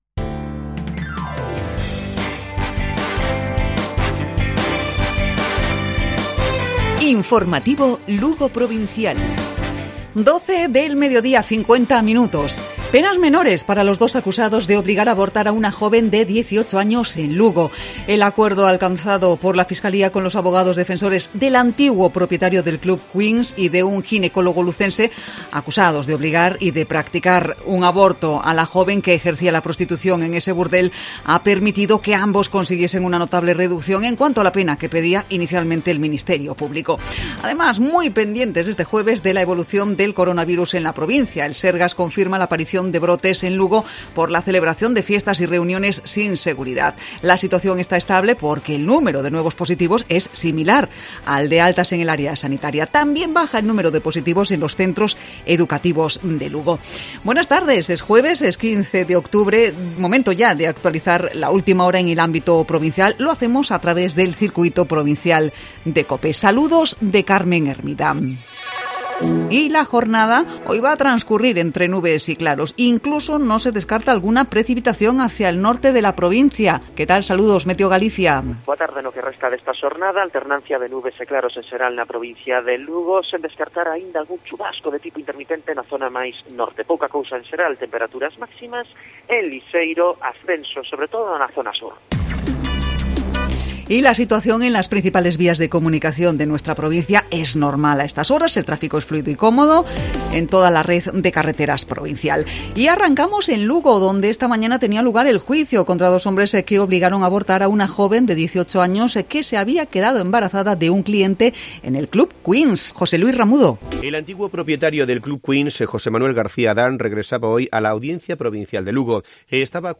Informativo Provincial Cope Lugo. Jueves, 15 de octubre. 12,50 horas